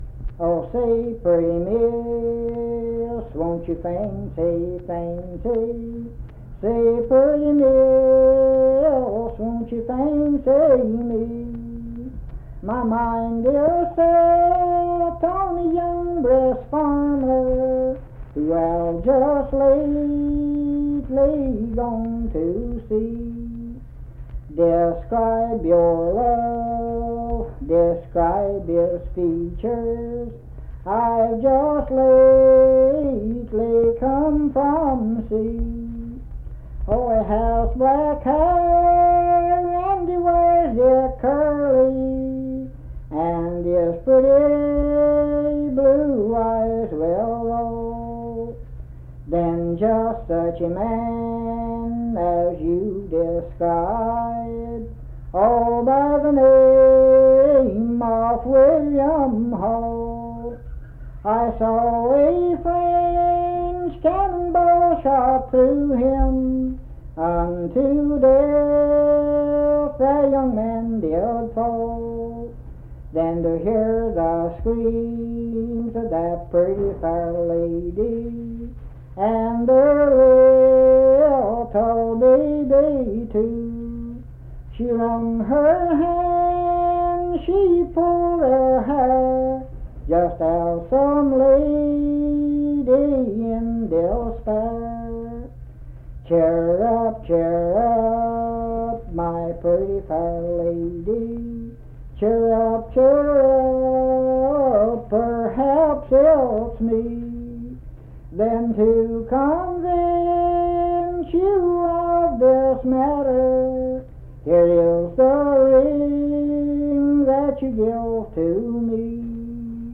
Unaccompanied vocal music
Verse-refrain 6(4).
Performed in Naoma, Raleigh County, WV.
Voice (sung)